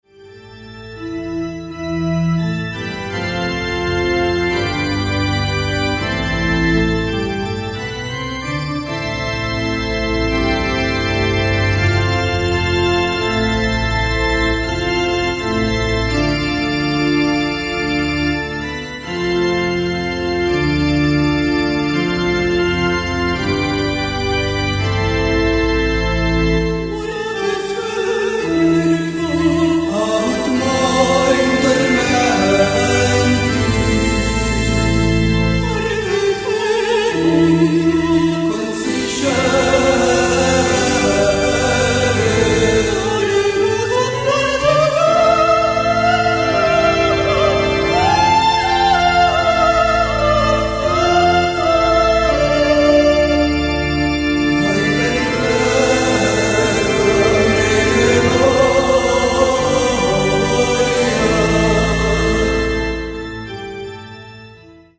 イタリアのベテランゴシックフォークバンド初期作品リマスター版
acoustic guitar, electric guitar, drum machine
keyboards, piano, pipe-organ